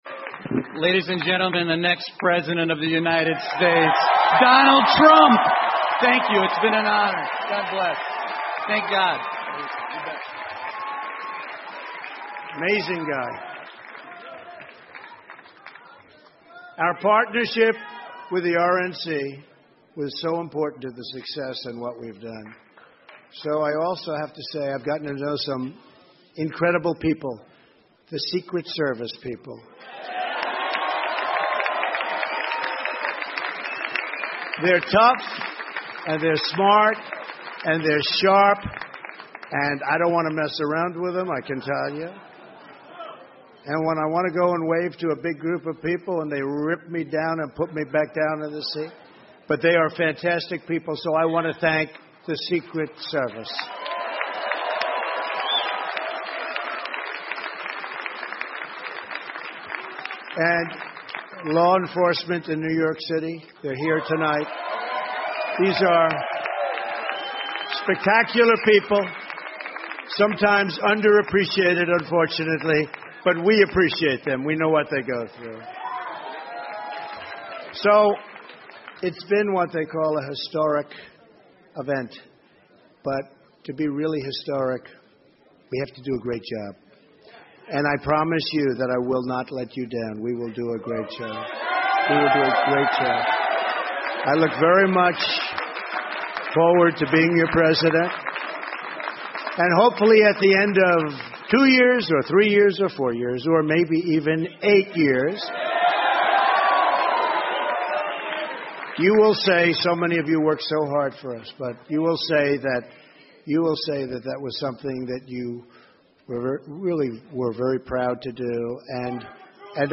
美国总统大选演讲 听力文件下载—在线英语听力室